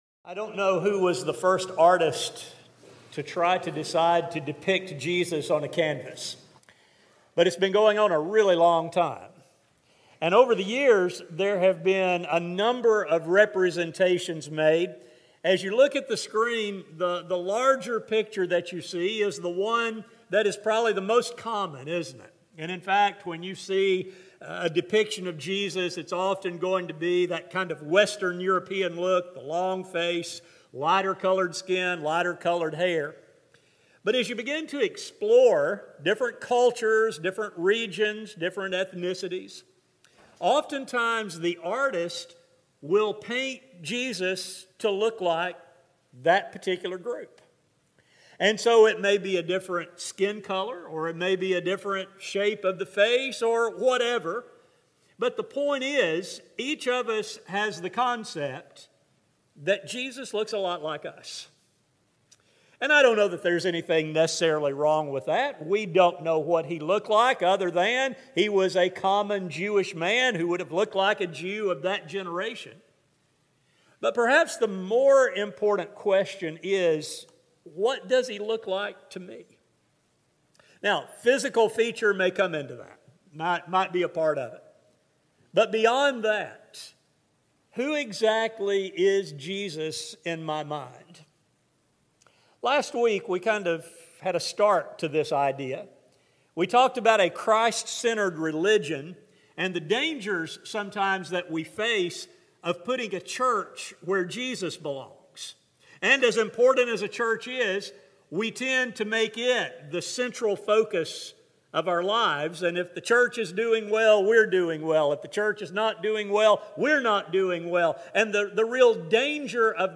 One of these occasions occurred as Jesus walked with His disciples and allowed Him the opportunity to rebuke a false picture while explaining who He is and what He expects. A sermon